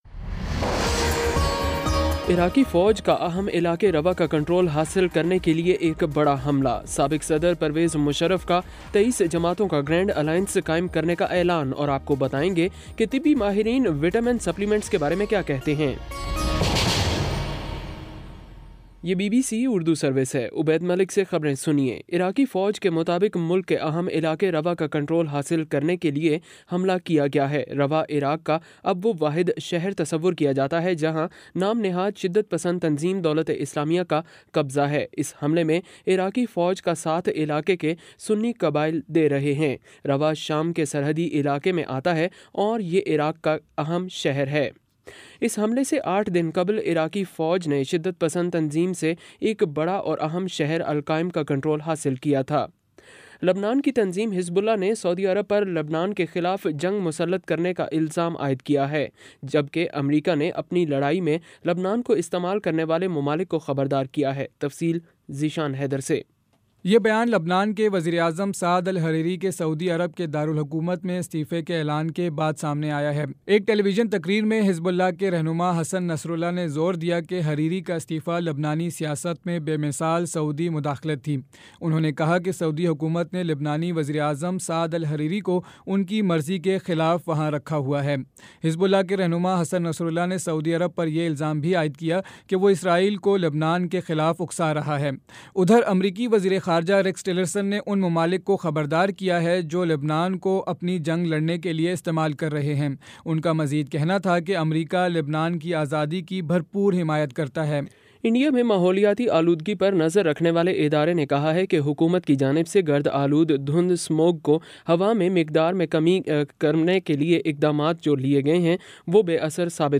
نومبر 11 : شام سات بجے کا نیوز بُلیٹن